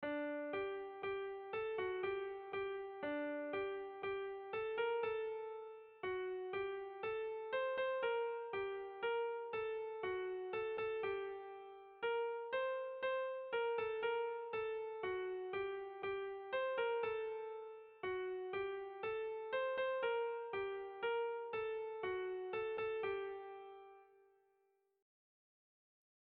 Gabonetakoa
Zaldibia < Goierri < Gipuzkoa < Basque Country
Zortziko txikia (hg) / Lau puntuko txikia (ip)
ABCB